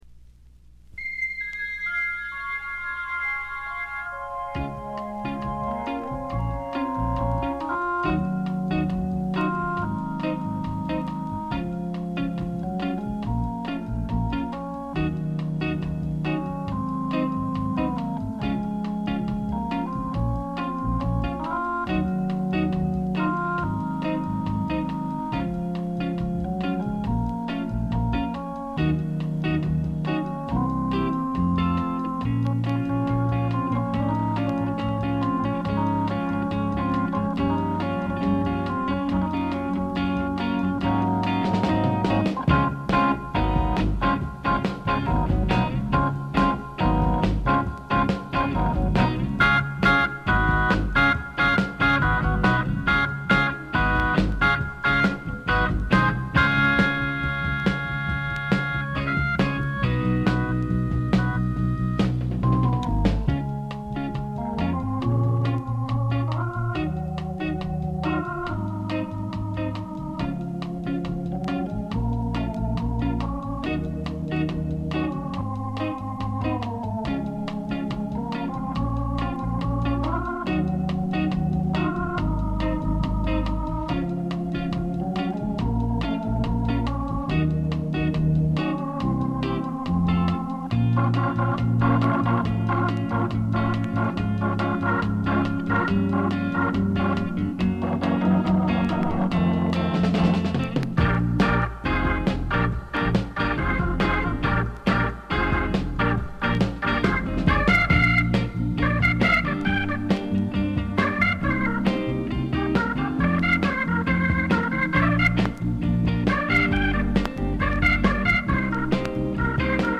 全体にチリプチ、プツ音多め大きめ。A4後半の周回ノイズ、B2フェードアウト部分あたりが目立つノイズです。
試聴曲は現品からの取り込み音源です。